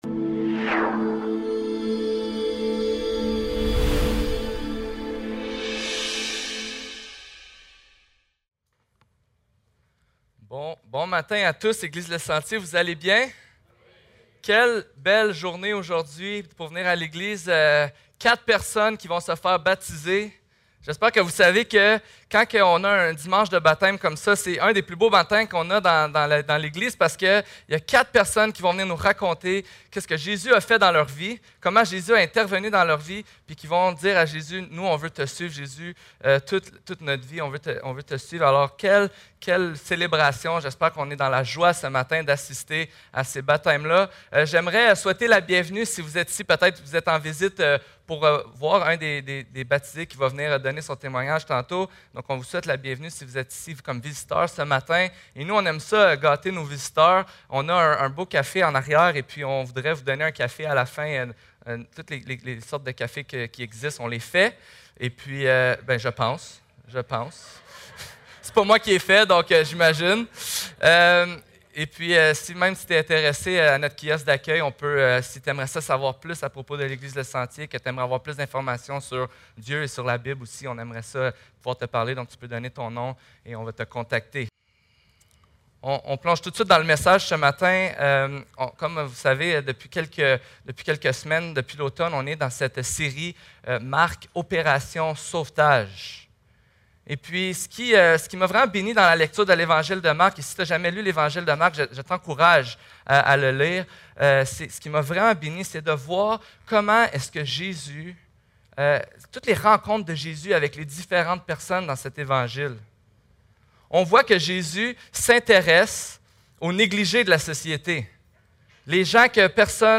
Baptêmes 24 novembre 2019 < église le Sentier | Jésus t'aime!